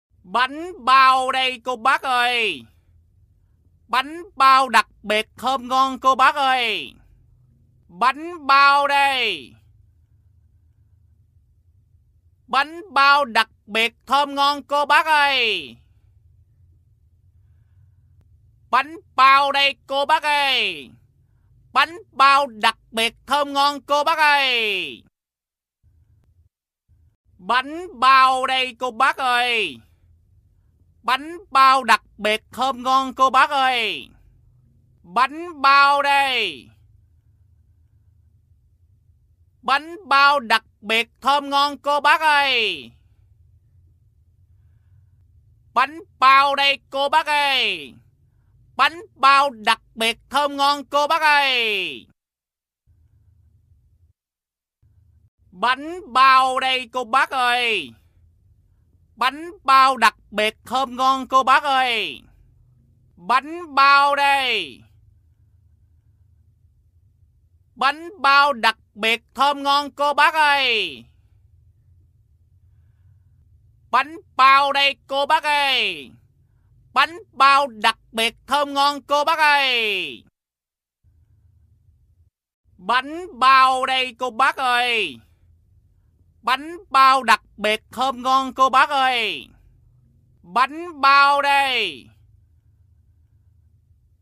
Tiếng rao ‘Bánh bao đây cô bác ơi, Bánh bao đặc biệt thơm ngon cô bác ơi…’
Thể loại: Tiếng con người
Description: Bánh bao đây cô bác ơi, bánh bao đặc biệt thơm ngon cô bác ơi, bánh bao đây... là tiếng rao bán bánh bao giọng miền tây. Âm thanh vang lên rõ ràng, rành mạch, giọng điệu nghe rất là thân thiện và truyền cảm. Tiếng rao vang lên thông báo đầy đủ thông tin sản phẩm, chất lượng, giá thành như thế nào giúp người mua bánh bao nắm bắt thông tin một cách nhanh nhất.
tieng-rao-banh-bao-day-co-bac-oi-banh-bao-dac-biet-thom-ngon-co-bac-oi-www_tiengdong_com.mp3